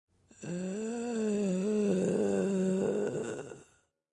Sound Effects
Zombie Moan